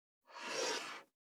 391,机の上をスライドさせる,スー,
効果音